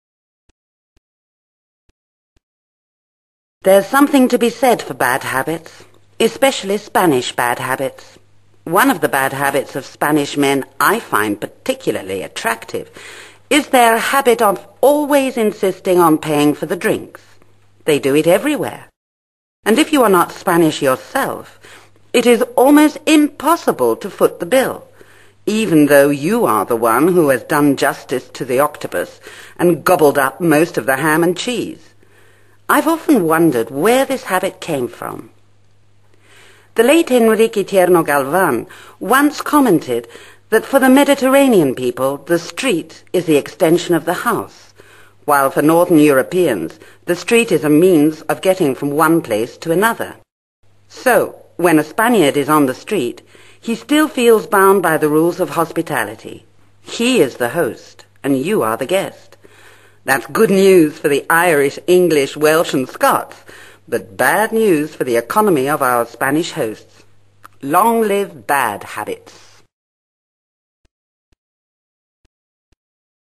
A Spanish Accent
This woman from Madrid talks about Spanish "bad habits"
La oradora es nativa de Madrid, España, y se observa un fuerte acento español en su pronunciación.
SPAIN.mp3